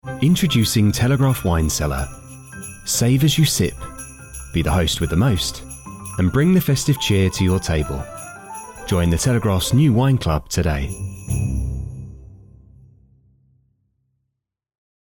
Social Ad – The Telegraph
BRITISH MALE VOICE-OVER ARTIST
Warm, neutral (non-regional) English accent
Telegraph-Wine-Cellar-Voiceover.mp3